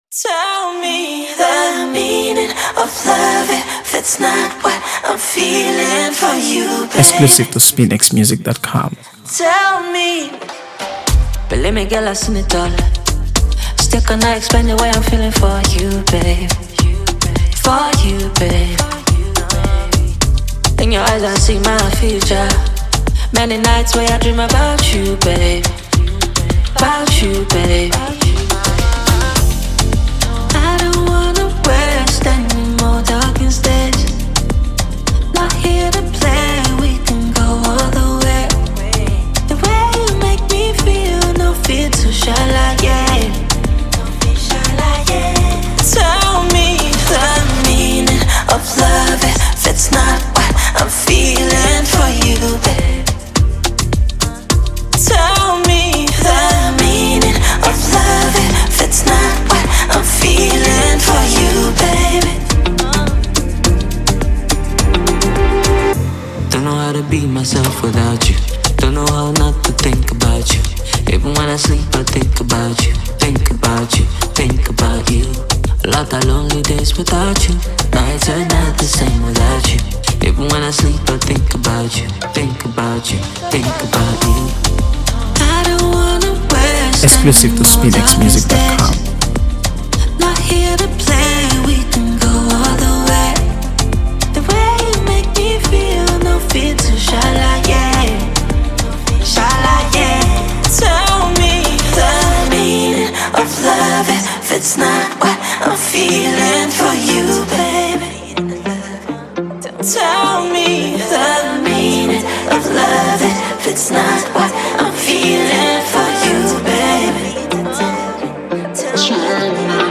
AfroBeats | AfroBeats songs
soulful melodies and heartfelt lyrics